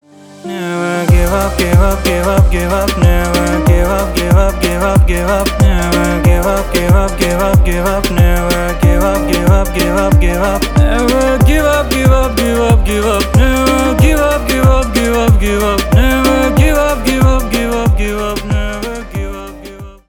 Поп Музыка # без слов